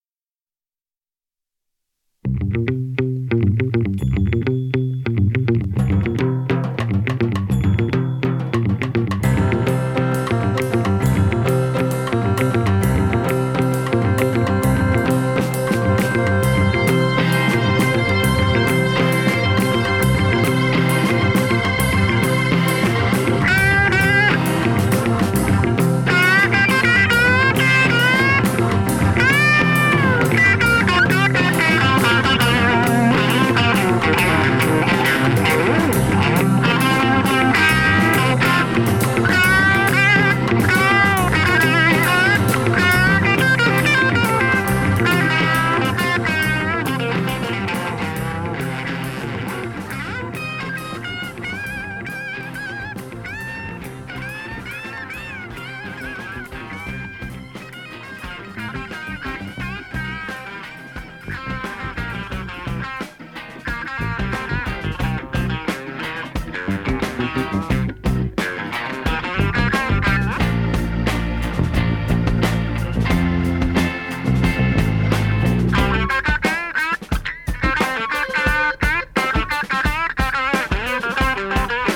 swung to the rock side of British Blues